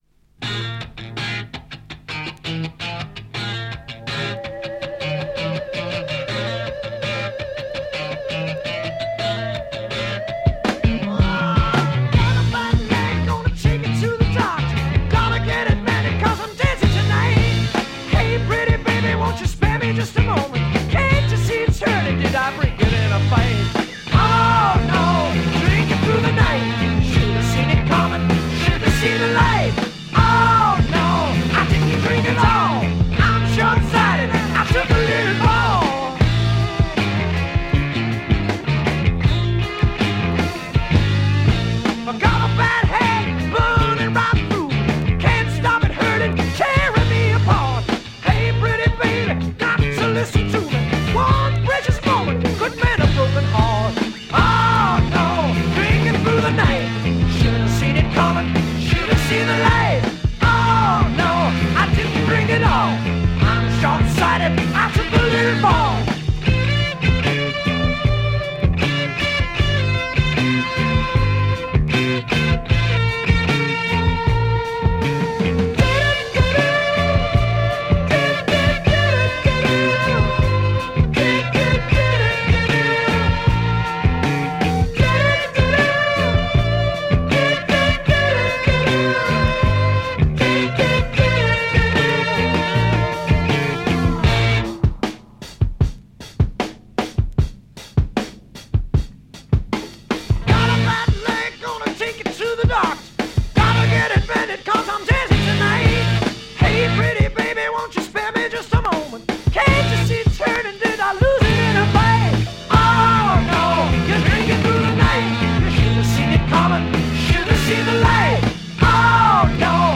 UK Prog rock